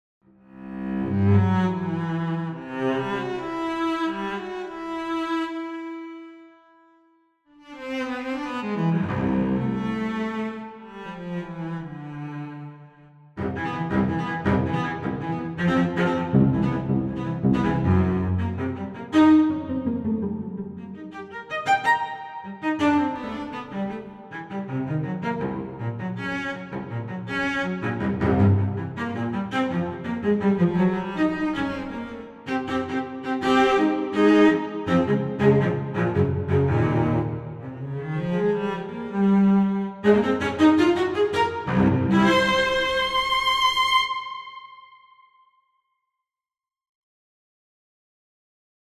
Opus solo cello, is it really bad?
On the contrary, I find it very good.
A good instrument played by a good musician, it seems to me.
Some really good digs in that improv, great example of what the library can do.
EW_cello.mp3